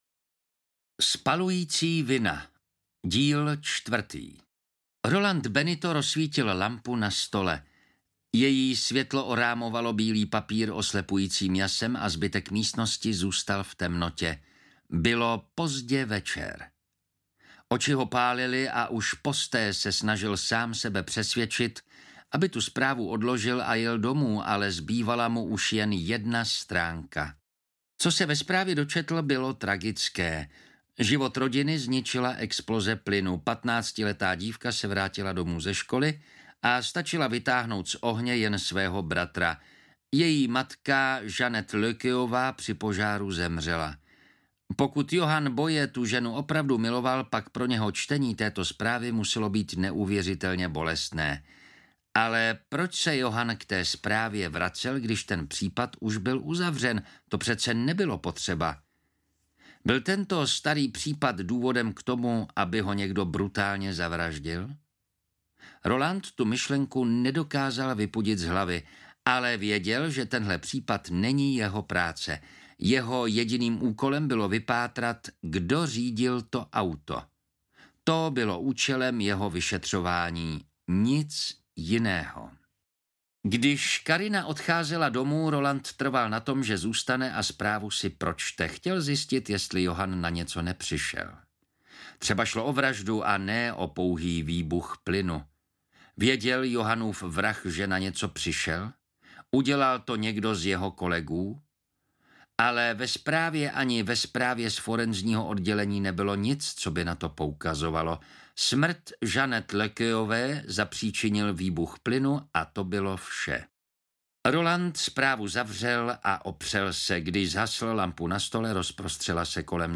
Spalující vina - Díl 4 audiokniha
Ukázka z knihy